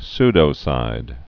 (sdō-sīd)